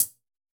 UHH_ElectroHatD_Hit-11.wav